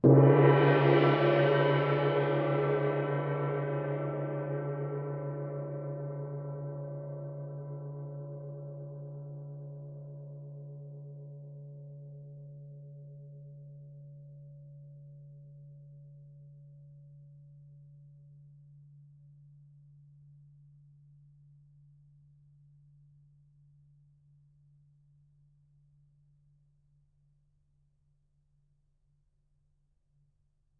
Percussion
gongHit_f.wav